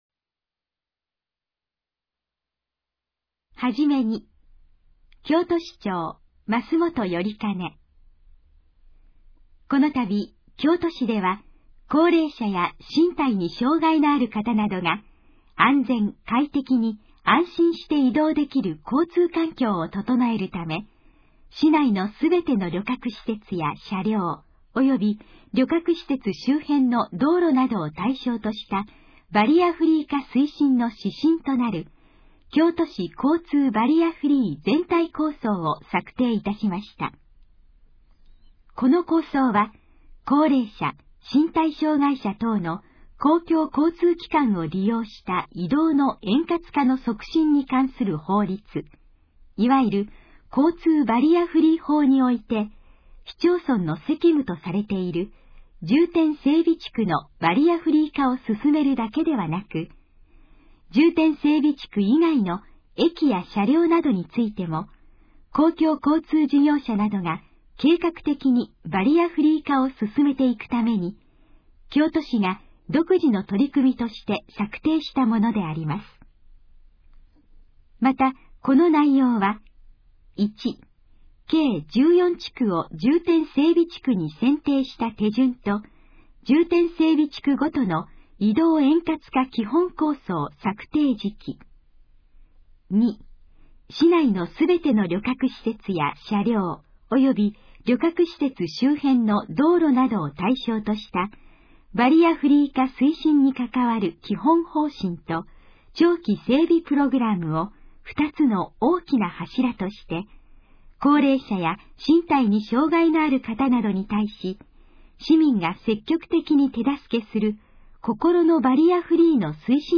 このページの要約を音声で読み上げます。
ナレーション再生 約439KB